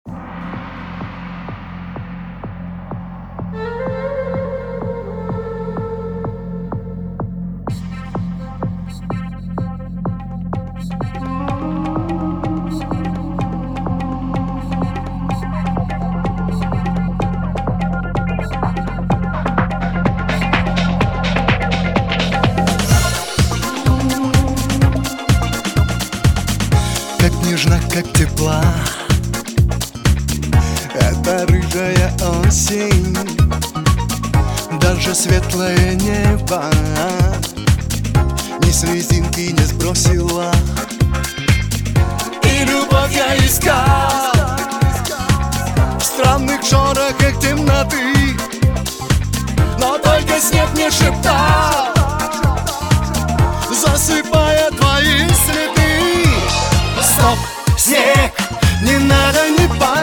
Pop
Этакая добротная советская эстрада времен Раймонда Паулса.